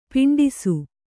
♪ piṇḍisu